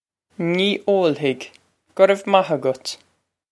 Pronunciation for how to say
Nee ole-hig, guh rev mah a-gut.
This is an approximate phonetic pronunciation of the phrase.